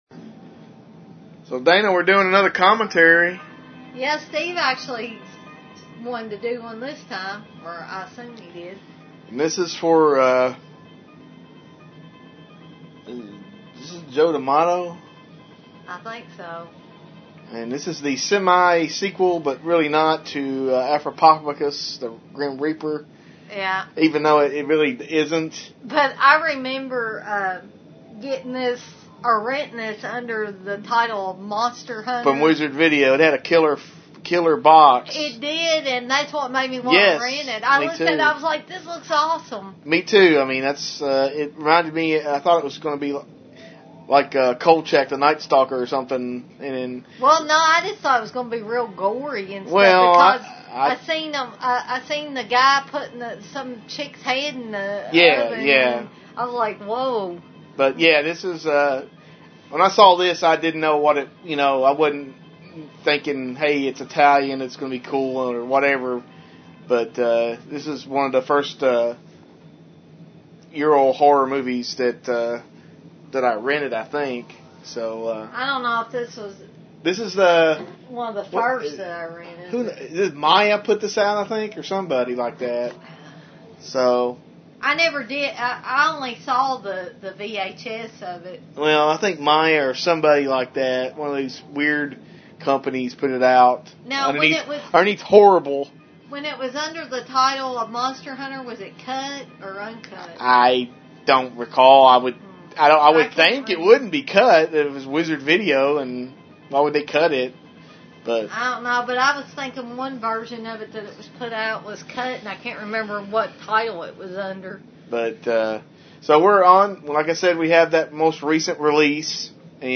Episode 57: Absurd Fan Commentary!